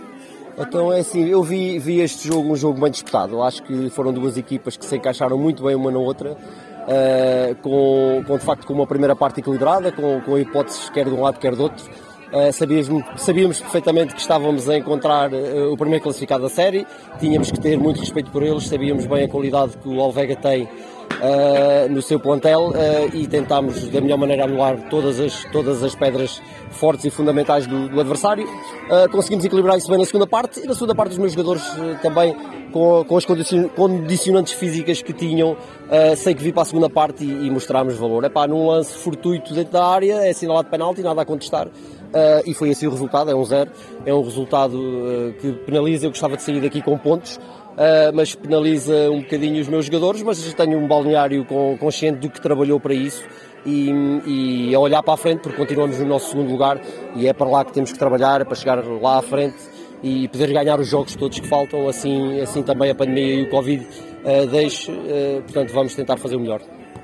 Como habitualmente fomos escutar os responsáveis técnicos de ambas as equipas: